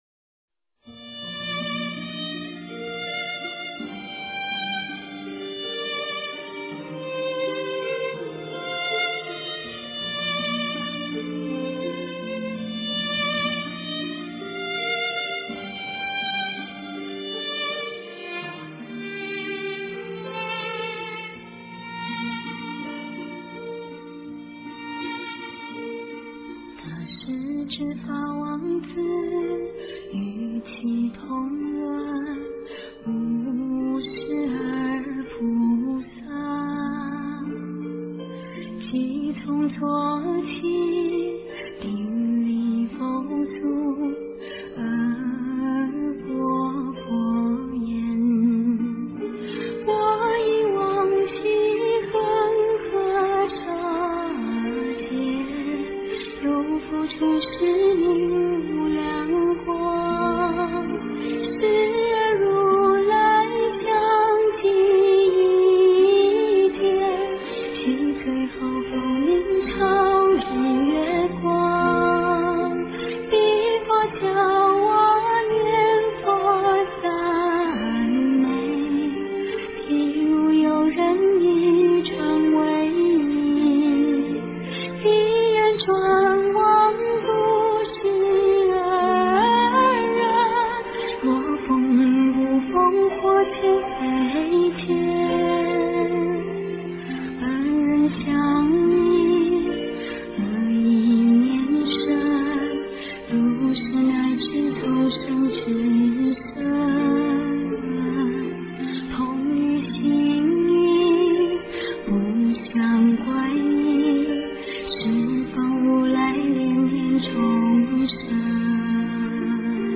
大势至菩萨念佛圆通章 诵经 大势至菩萨念佛圆通章--居士唱诵 点我： 标签: 佛音 诵经 佛教音乐 返回列表 上一篇： 地藏菩萨本愿经 下一篇： 大势至菩萨念佛圆通章 相关文章 貧僧有話34說：我写作的因缘--释星云 貧僧有話34說：我写作的因缘--释星云...